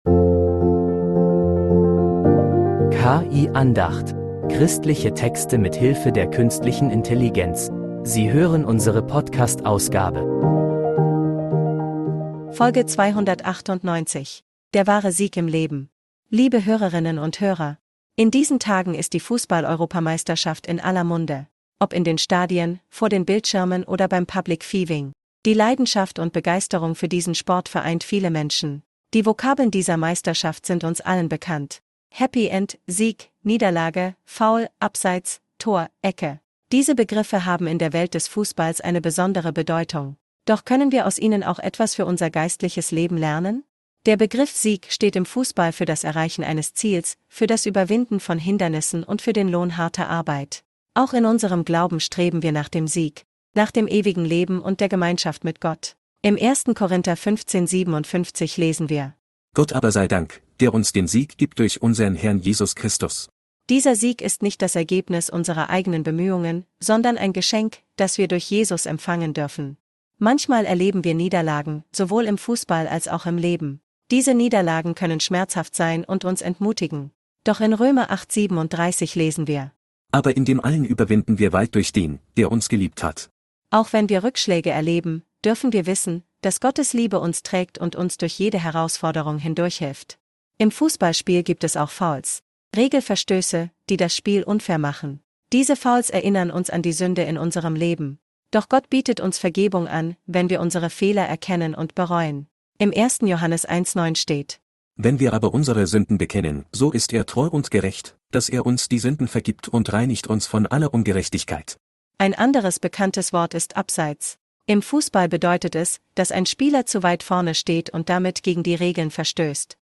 In dieser Predigt wird die Begeisterung für die